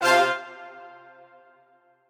strings5_14.ogg